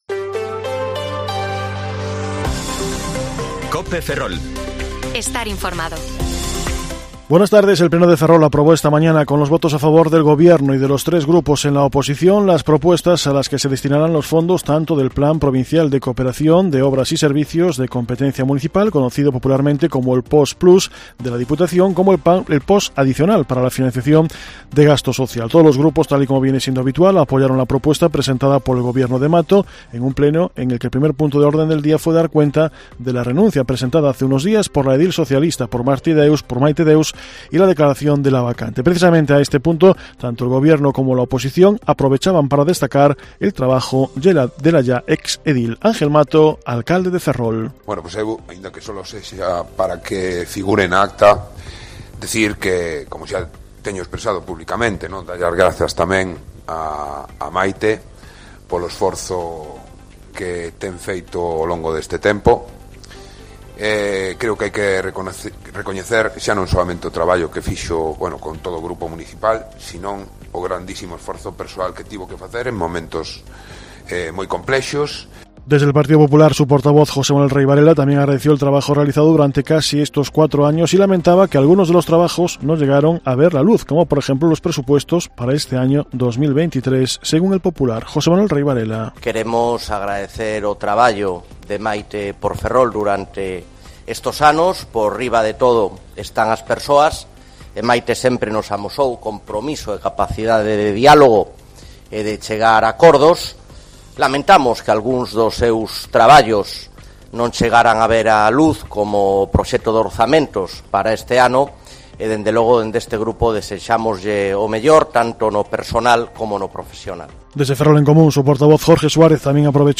Informativo Mediodía COPE Ferrol 13/2/2023 (De 14,20 a 14,30 horas)